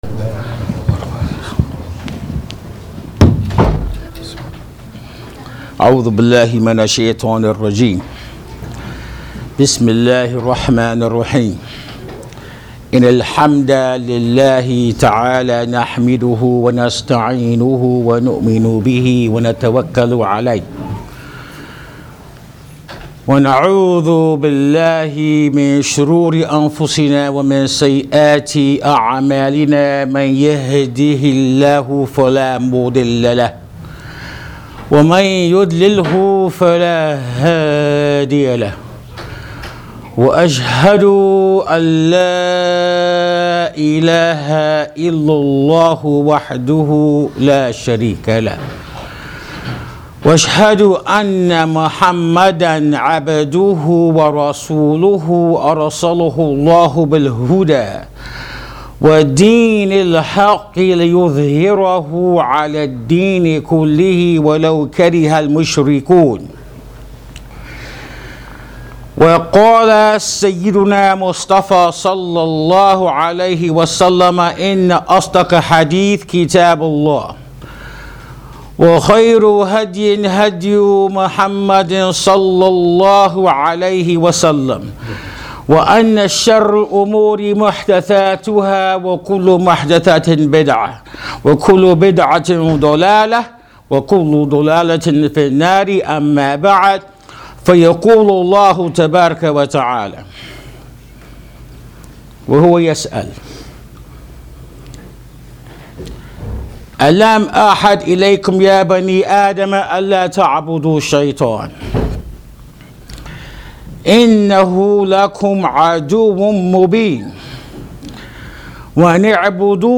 Free Audio Khutba